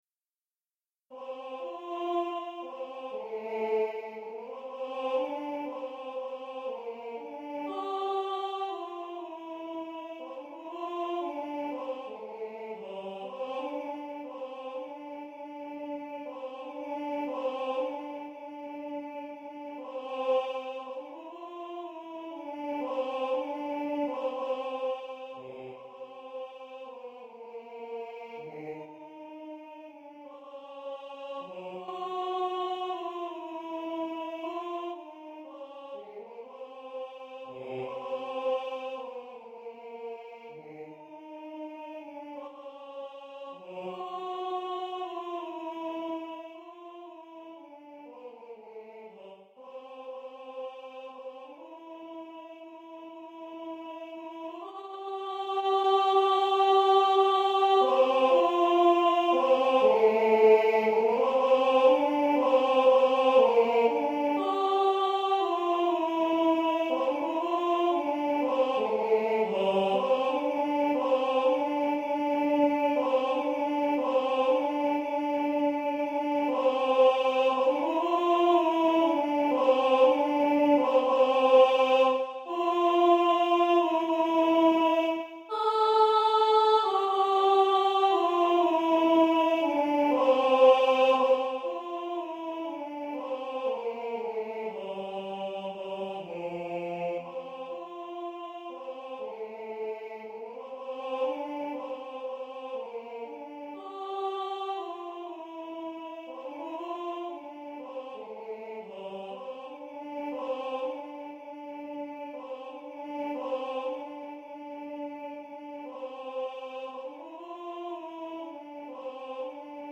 Partitions et enregistrements audio séquenceur du morceau Tannhauser - Choeur des Pèlerins, de Richard Wagner, Classique.
Genre: Classique
tannhauser_-_tenor.mp3